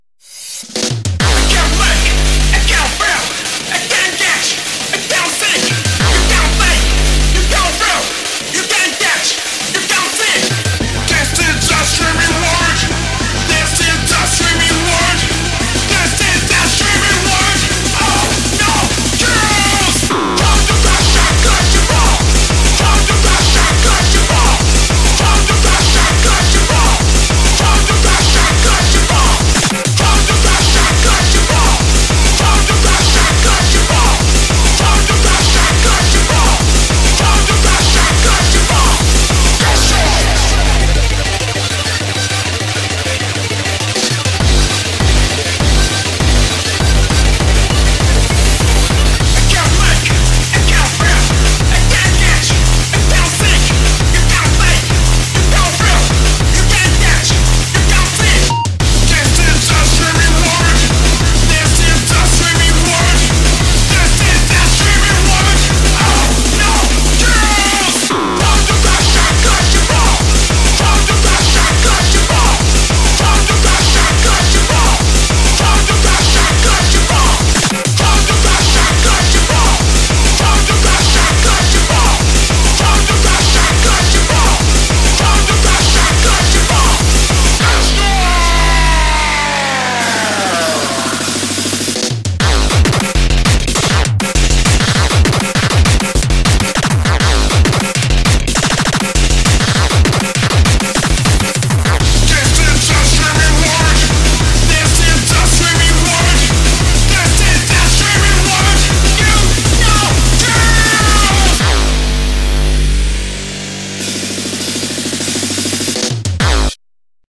BPM200
Audio QualityPerfect (Low Quality)